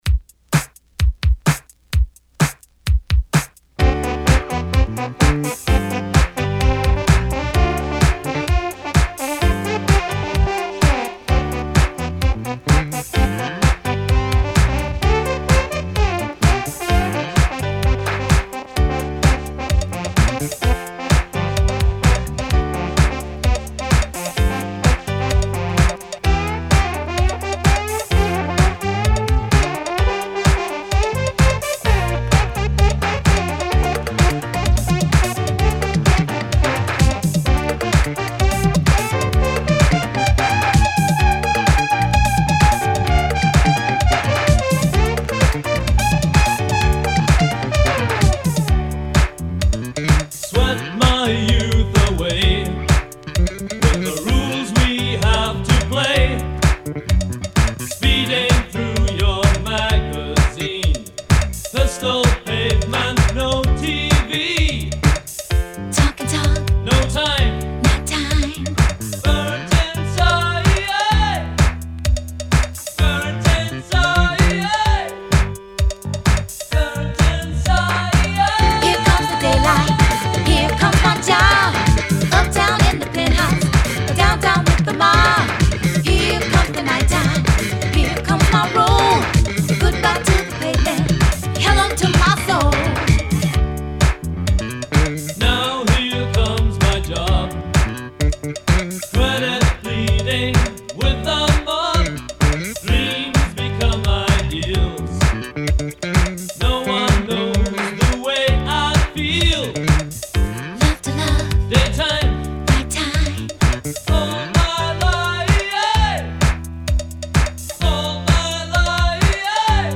so please enjoy the vinyl rip that I have included below.